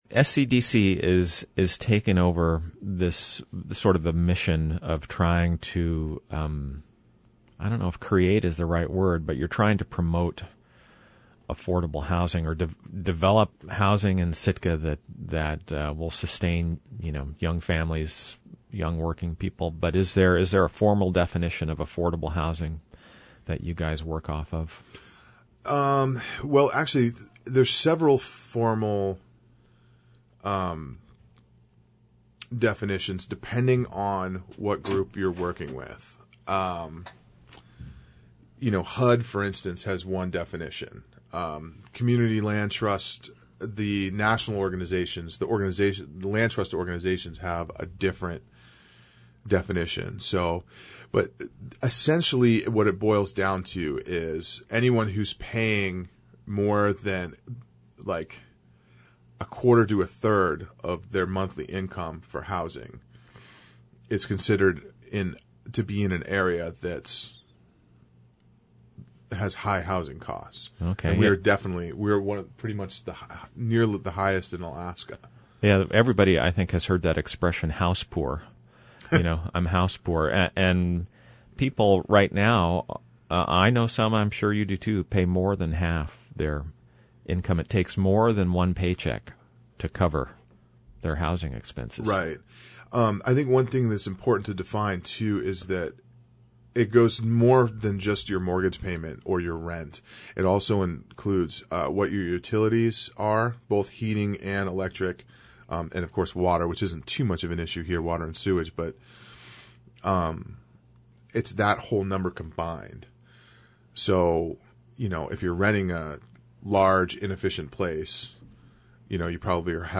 Interview: Land trusts can close housing gap - KCAW